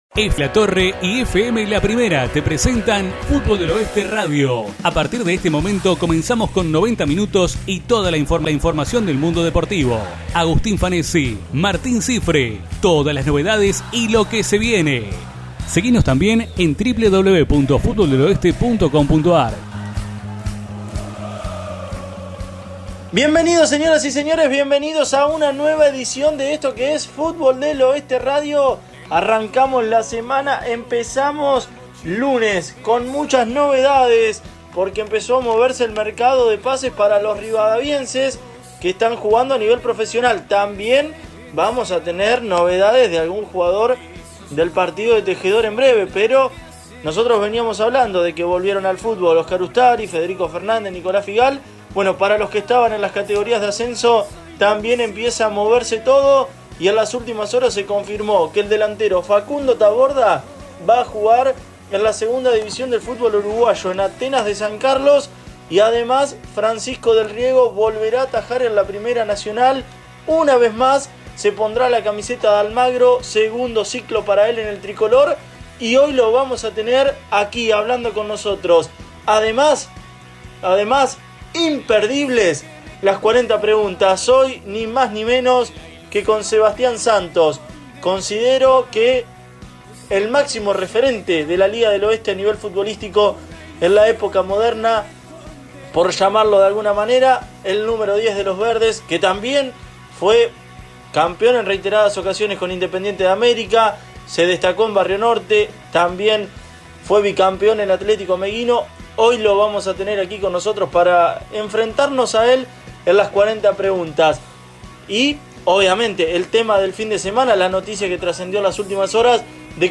Nuestro programa radial tuvo su 47ª edición de este 2020, aquí te acercamos los 2 bloques para que puedas escucharlo a través de tu computadora o dispositivo móvil.